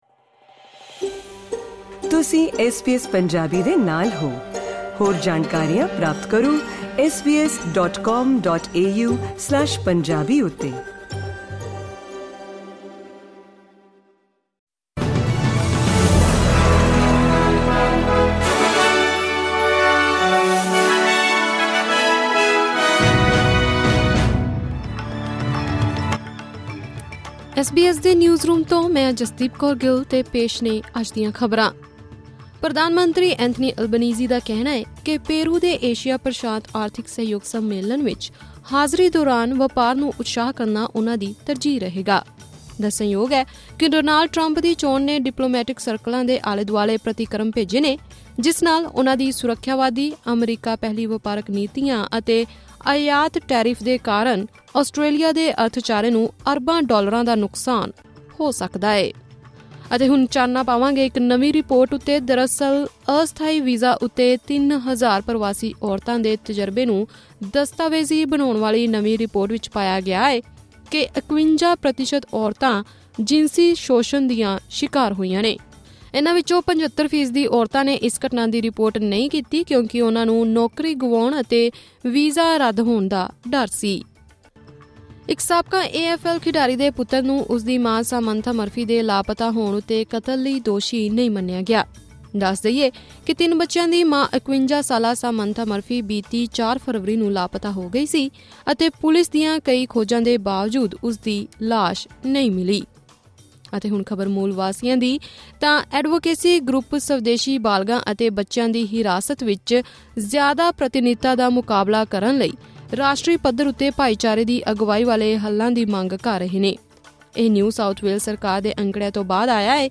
ਐਸ ਬੀ ਐਸ ਪੰਜਾਬੀ ਤੋਂ ਆਸਟ੍ਰੇਲੀਆ ਦੀਆਂ ਮੁੱਖ ਖ਼ਬਰਾਂ: 14 ਨਵੰਬਰ 2024